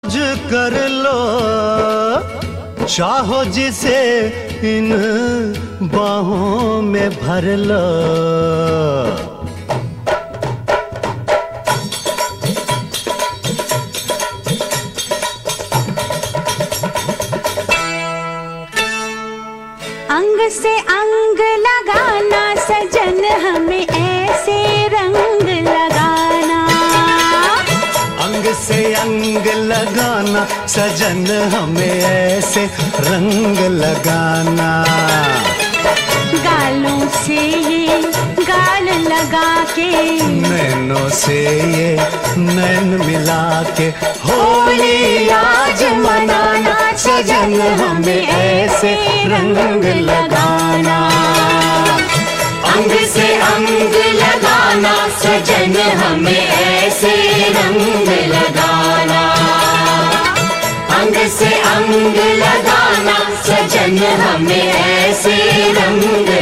Download for free and enjoy the melodious vibes!